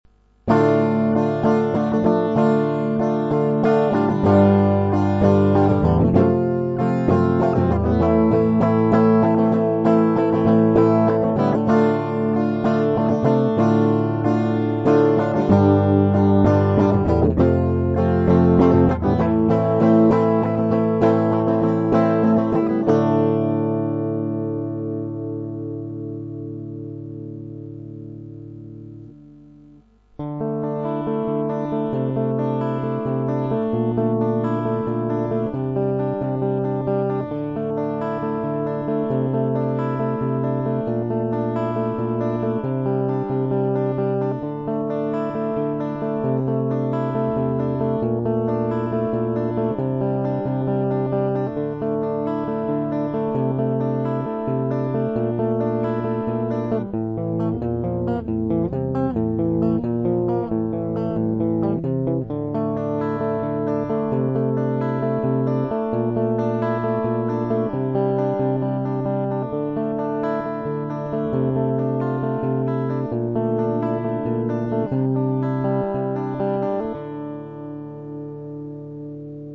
- припев (треть файла)